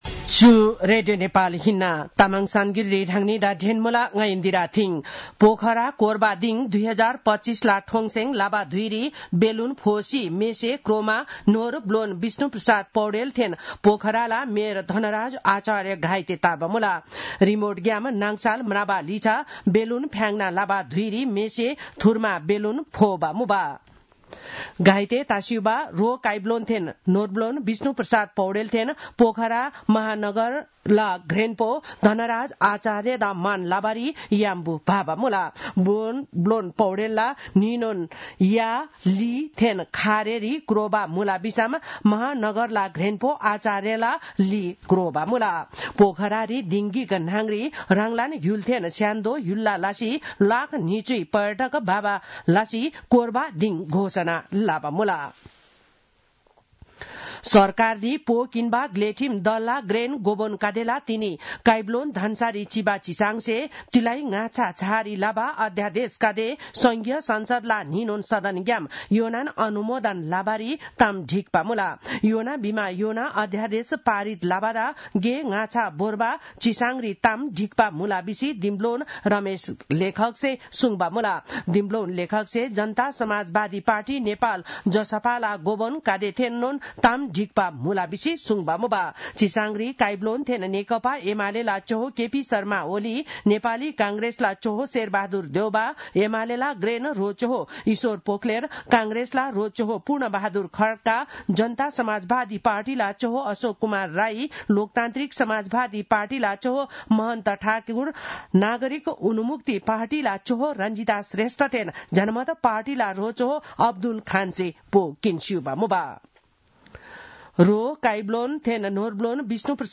तामाङ भाषाको समाचार : ४ फागुन , २०८१